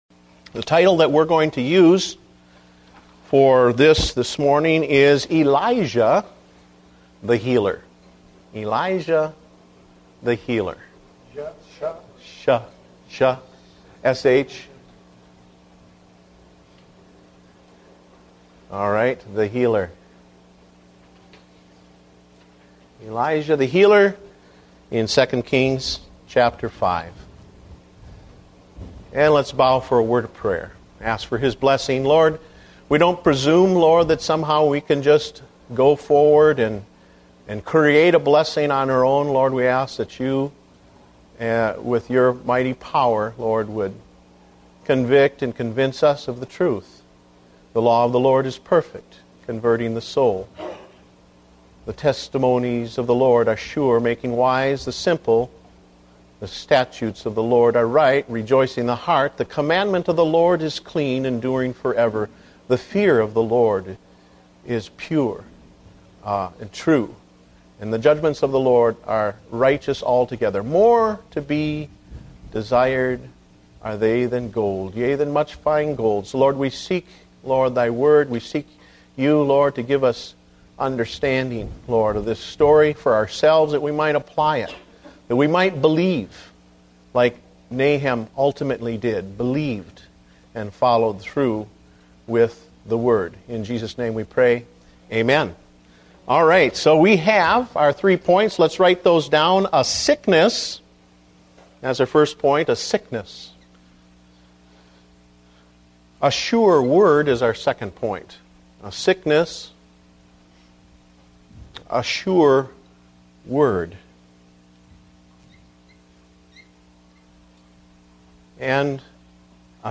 Date: November 22, 2009 (Adult Sunday School)